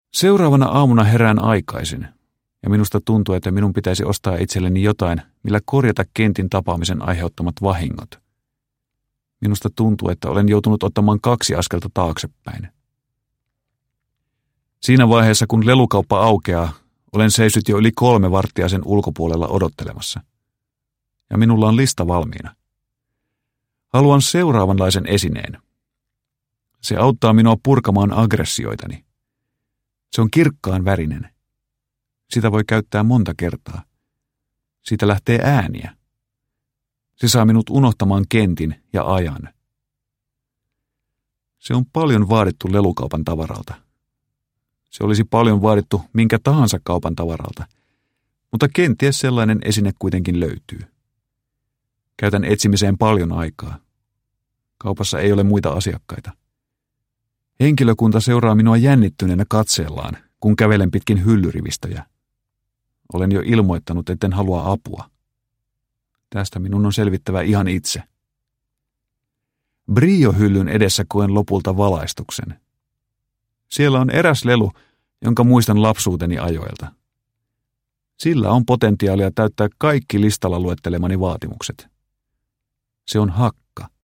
Supernaiivi – Ljudbok – Laddas ner
Uppläsare: